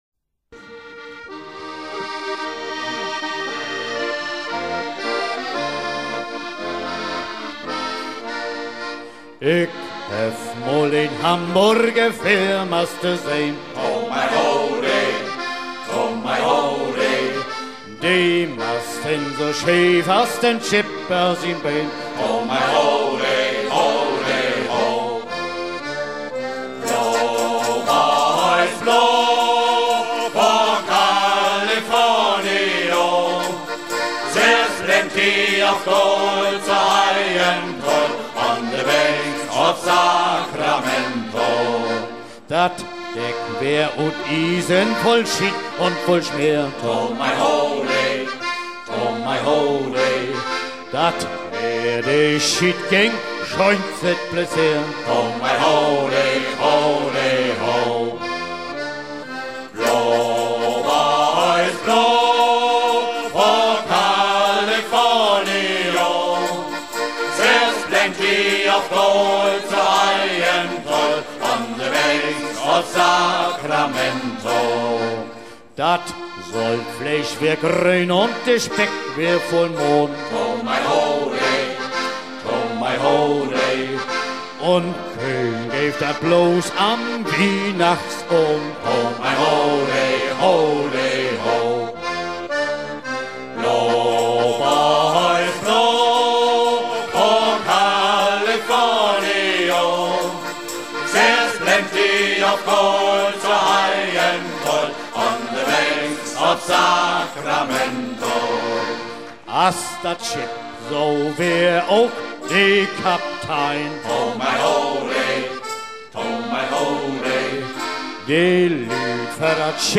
à virer au cabestan